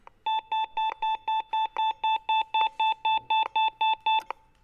Freigabesignal
Das schnelle Piepen (piep, piep, piep) signalisiert, dass jetzt Grün ist und hilft beim Überqueren der Straße.
Sie erhöhen oder reduzieren ihre Lautstärke in Abhängigkeit der Umgebungsgeräusche.
Die akustischen Signale wurden uns freundlicherweise von der Firma RTB zur Verfügung gestellt.